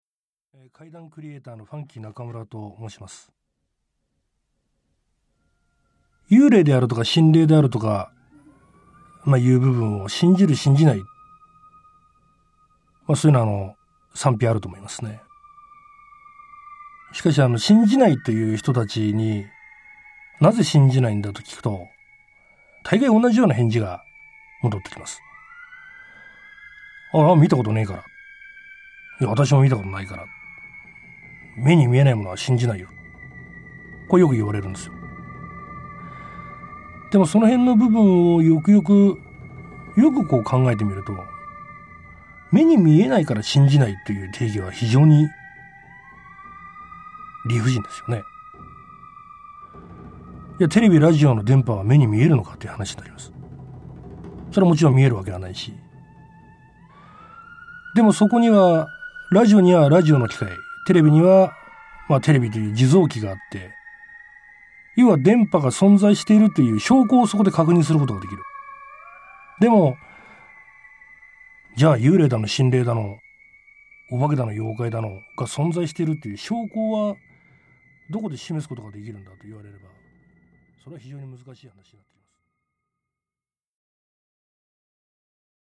[オーディオブック] ひとり怪談 第一話「北の街にて」